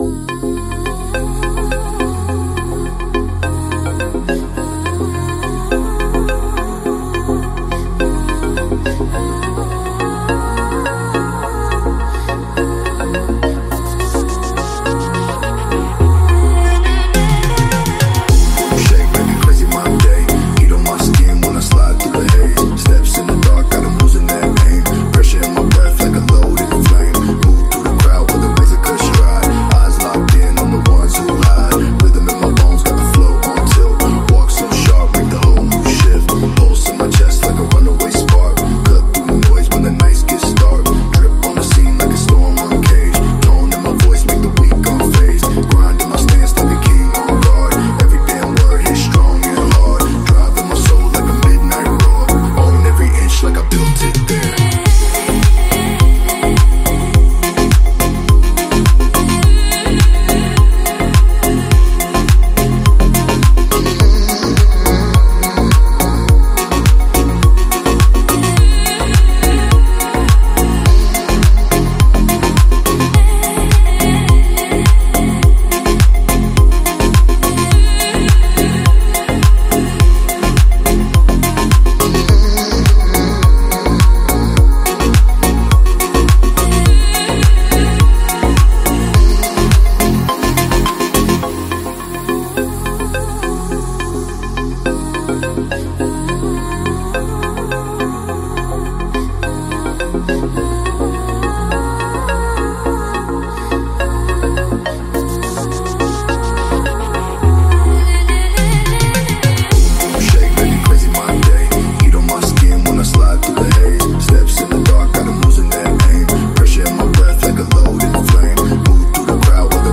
با ریتم‌های کوبنده و اتمسفر سینمایی
Deep، Epic، Dark و Cinematic